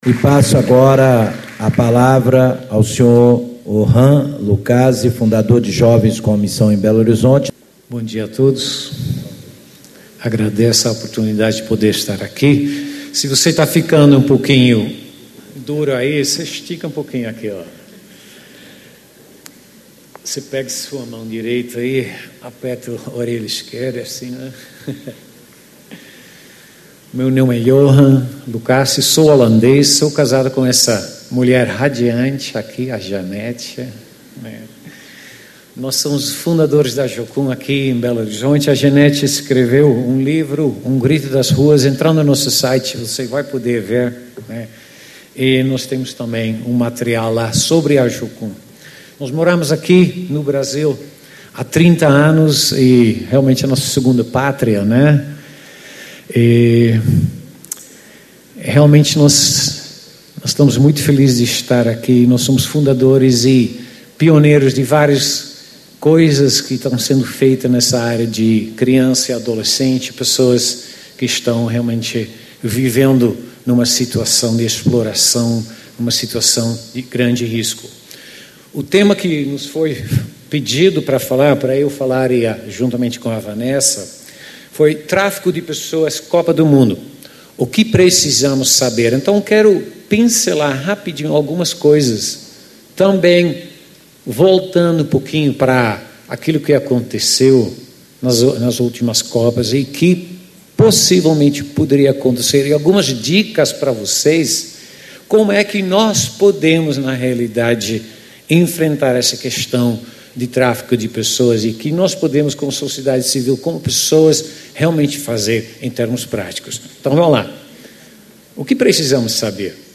Ciclo de Debates Enfrentamento do Tráfico de Pessoas em Minas Gerais
Discursos e Palestras